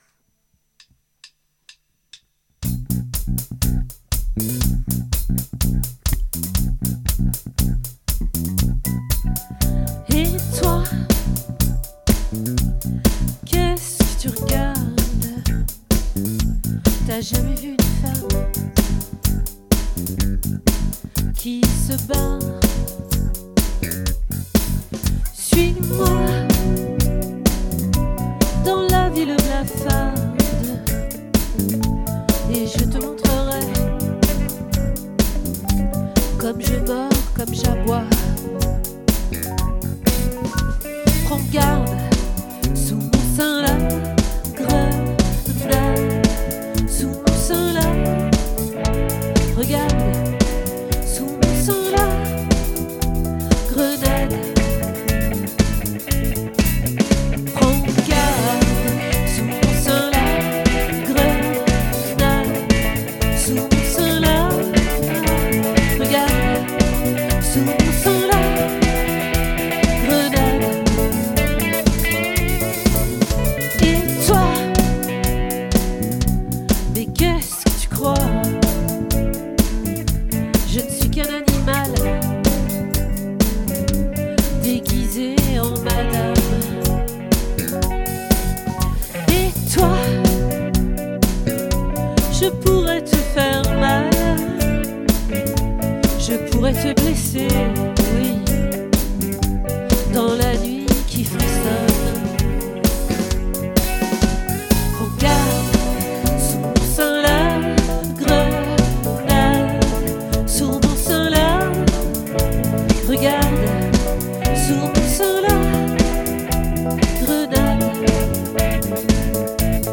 🏠 Accueil Repetitions Records_2024_01_29